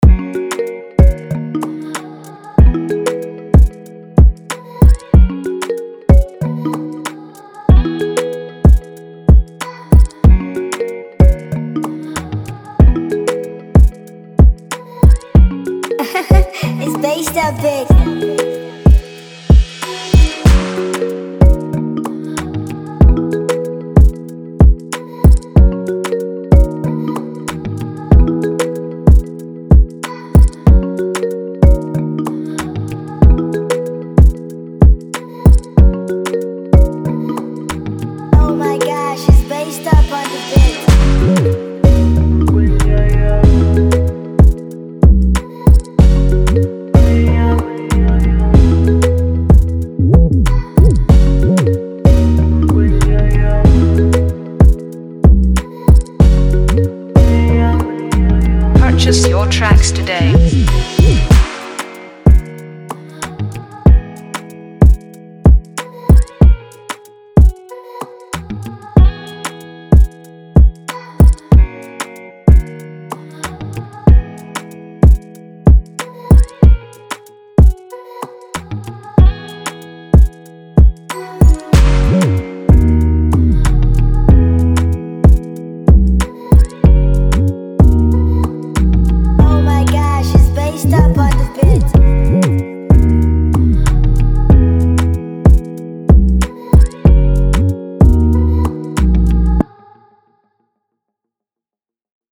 Download instrumental mp3 below…